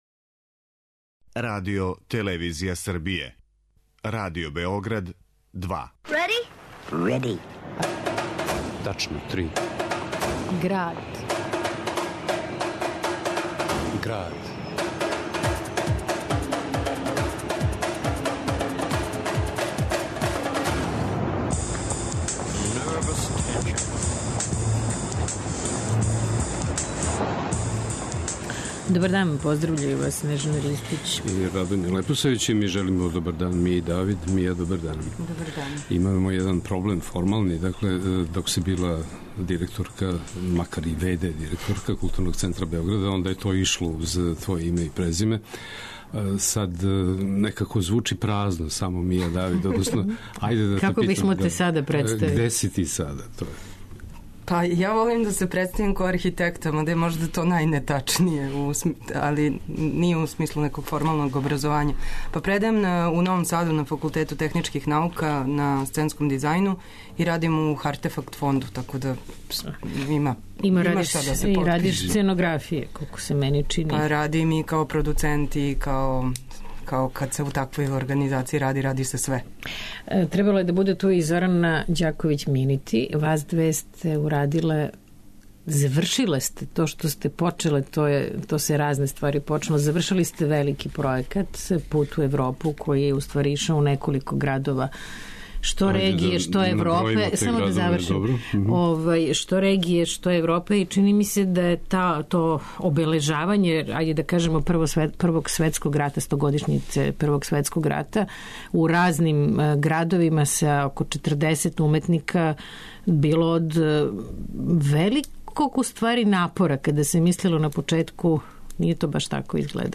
уз документарне снимке